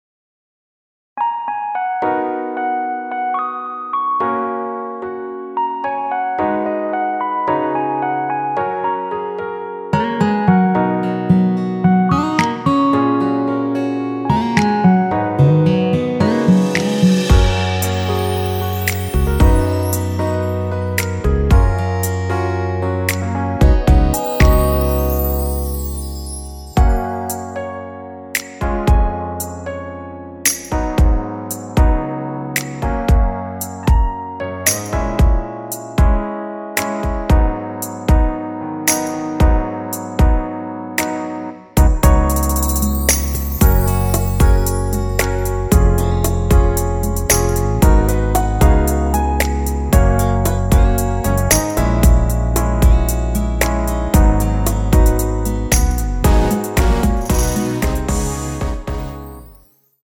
원키에서(-1)내린 MR입니다.
F#
앞부분30초, 뒷부분30초씩 편집해서 올려 드리고 있습니다.